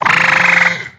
Cri de Batracné dans Pokémon X et Y.